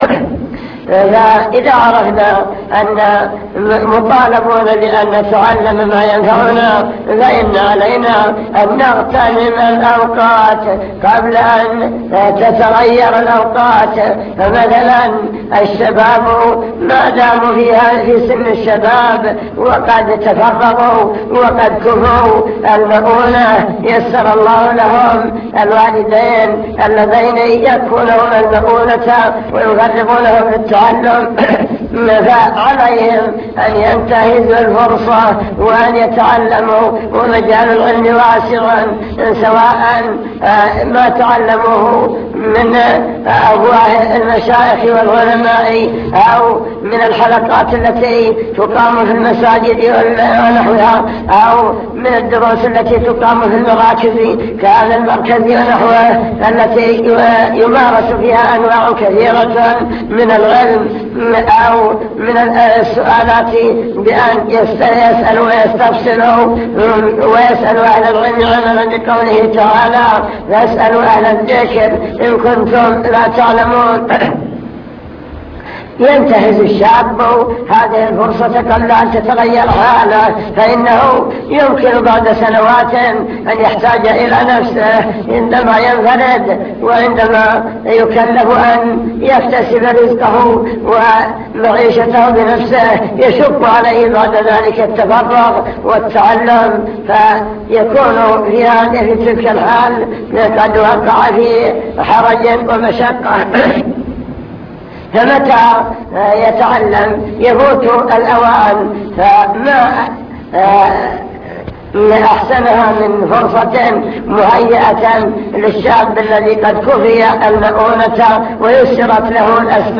المكتبة الصوتية  تسجيلات - محاضرات ودروس  محاضرات عن طلب العلم وفضل العلماء أهمية التفقه في الدين وكيفية تحقيق معنى العبودية